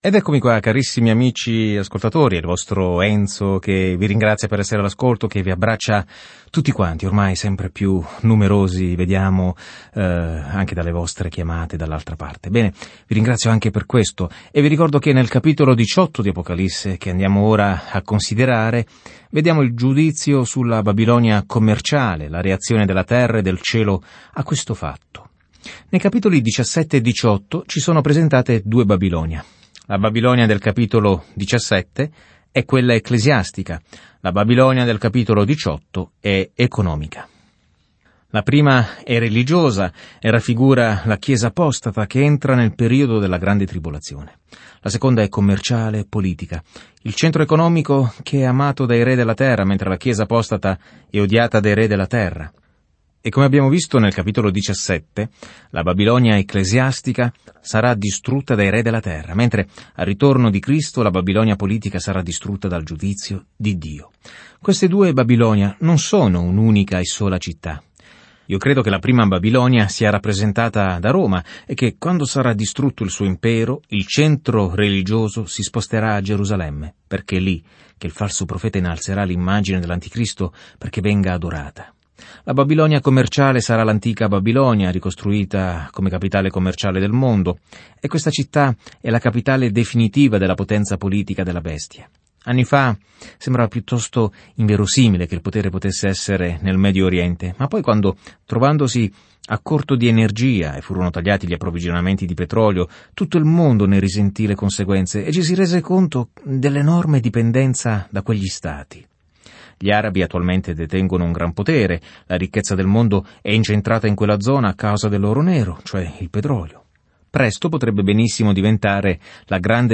Scrittura Apocalisse di Giovanni 18:1-8 Giorno 58 Inizia questo Piano Giorno 60 Riguardo questo Piano L’Apocalisse registra la fine dell’ampia linea temporale della storia con l’immagine di come il male verrà finalmente affrontato e il Signore Gesù Cristo governerà con ogni autorità, potere, bellezza e gloria. Viaggia ogni giorno attraverso l'Apocalisse mentre ascolti lo studio audio e leggi versetti selezionati della parola di Dio.